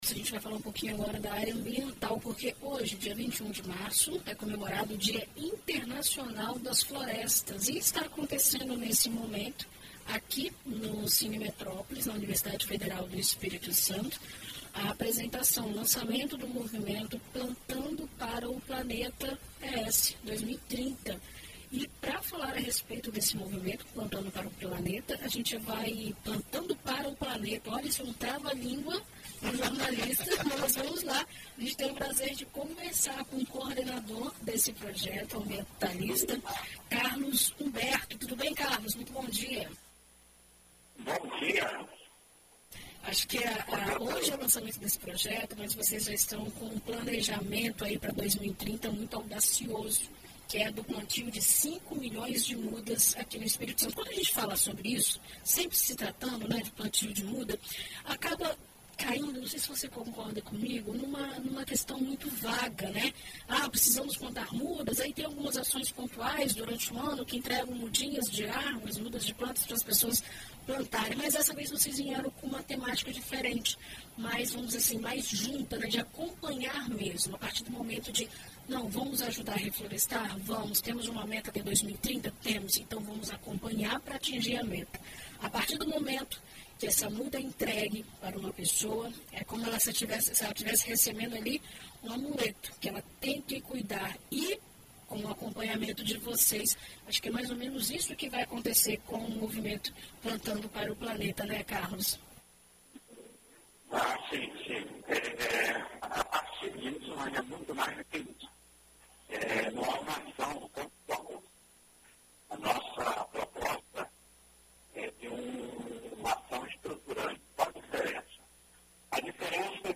Em entrevista à BandNews FM ES